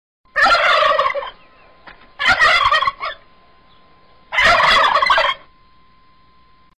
Turkey Sound Effect Free Download
Turkey